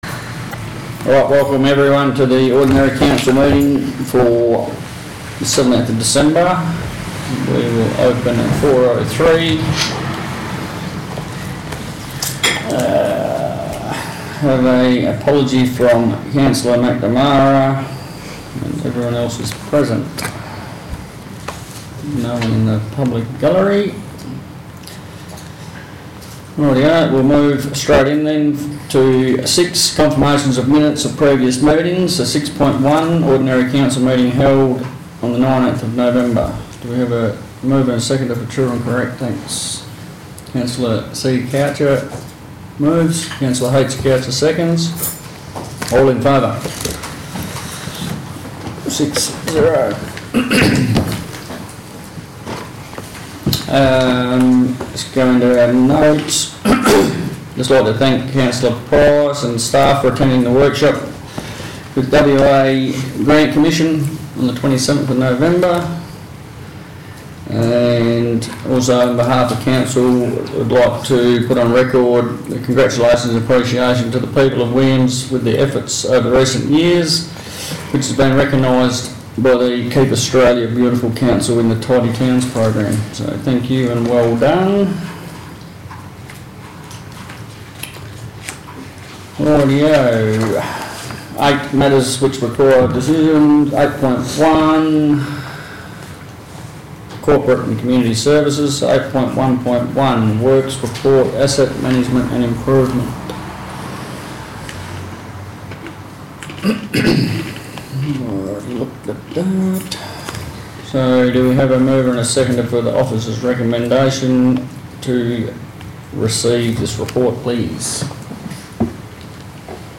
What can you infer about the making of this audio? Ordinary Meeting of Council - Wednesday 17th December 2025 » Shire of Williams